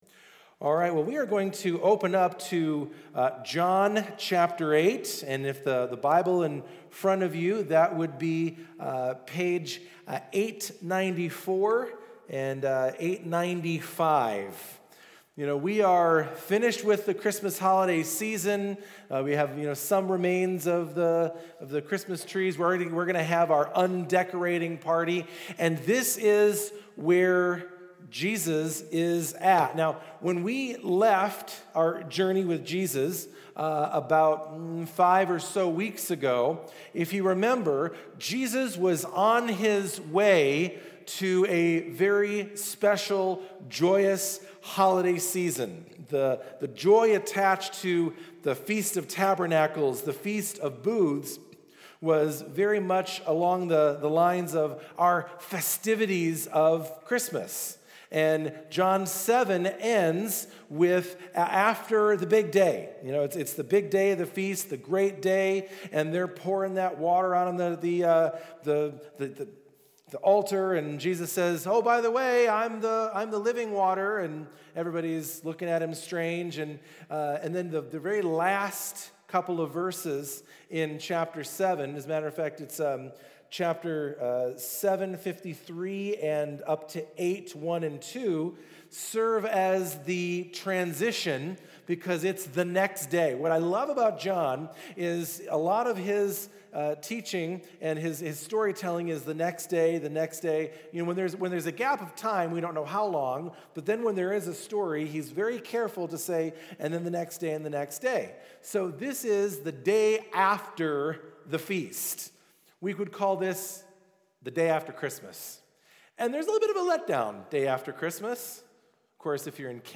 This Weeks Sermon